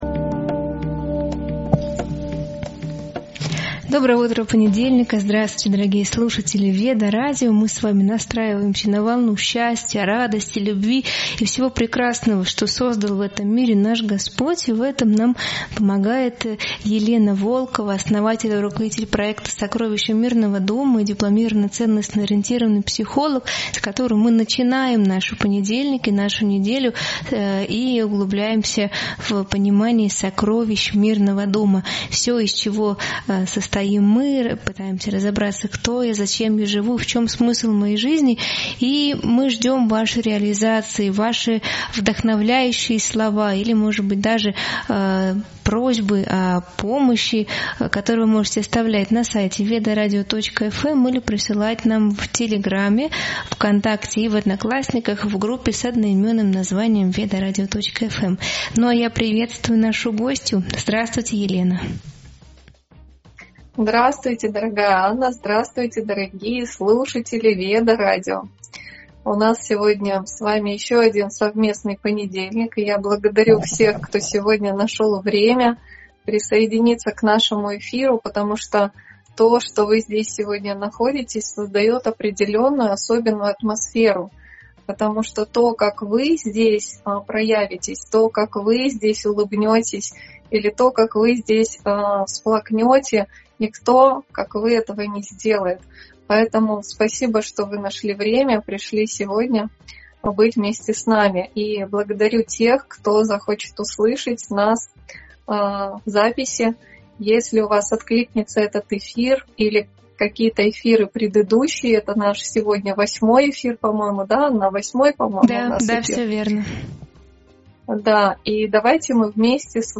00:00 — Доброго понедельника, слушатели! Эфир Веда-радио настраивает нас на волну счастья и любви, созданную Господом.